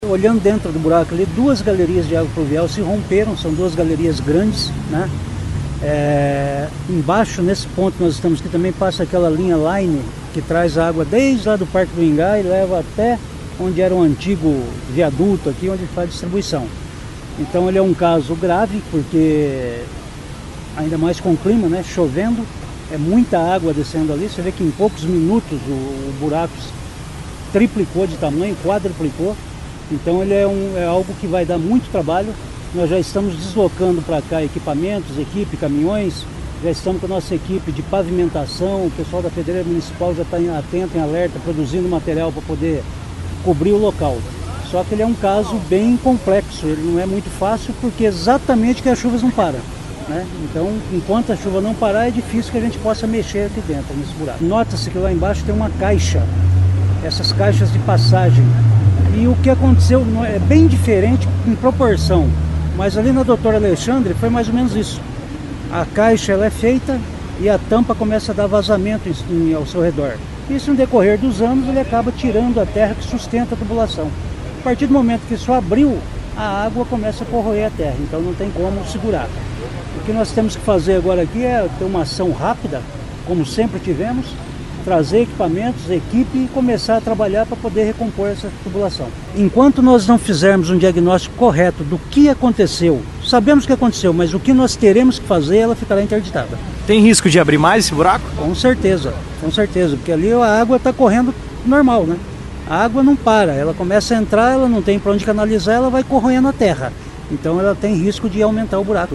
O secretário Vagner Mussio, da Defesa Civil, explica o que causou a abertura da cratera.